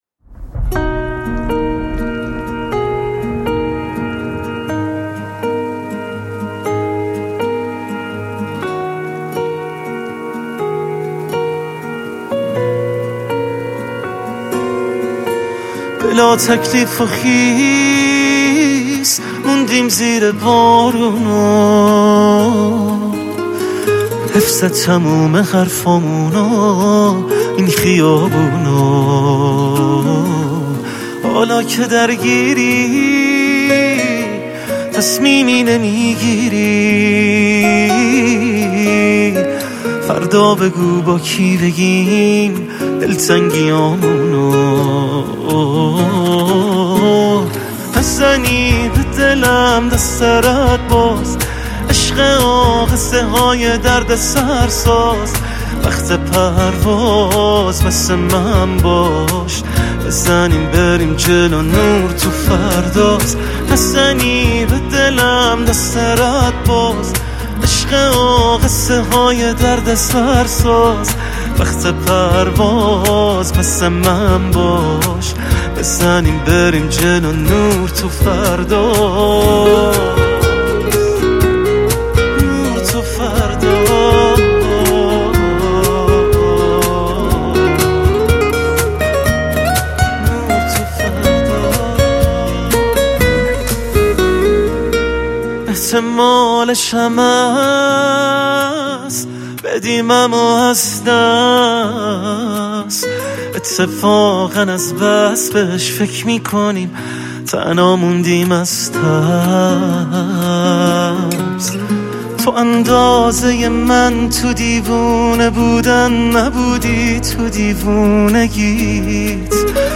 نسخه آنپلاگد